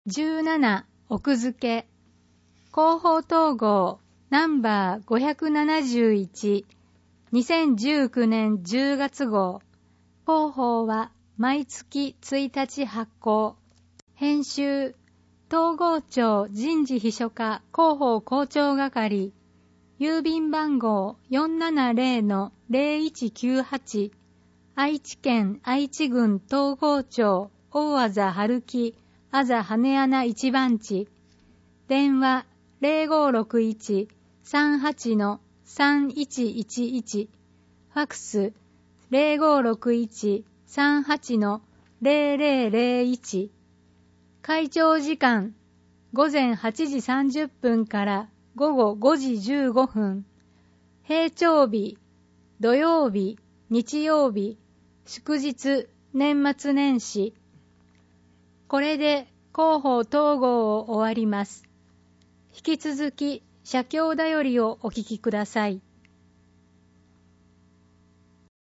広報とうごう音訳版（2019年10月号）